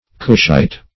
cushite - definition of cushite - synonyms, pronunciation, spelling from Free Dictionary
Cushite \Cush"ite\ (k[u^]sh"[imac]t), n.